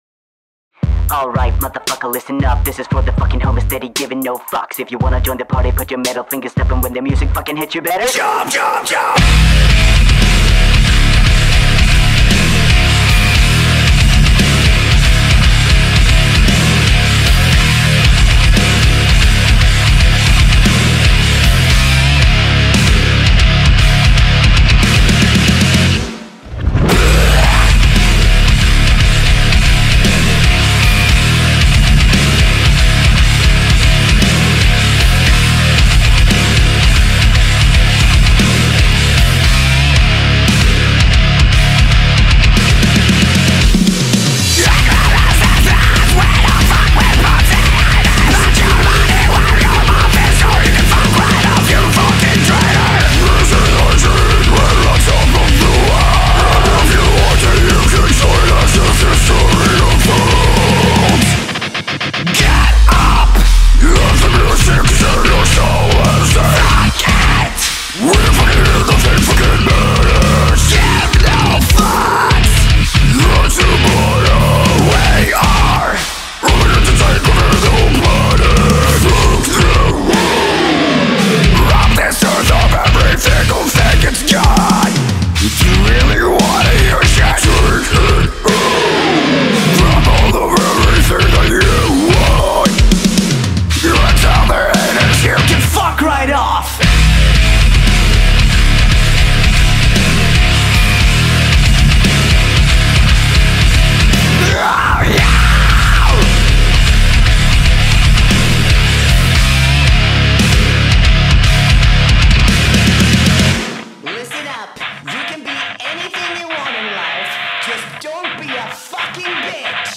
【Cover】
(I apologize for my incredibly shite rapping...)
Warning! There's a lot of swearing in this one!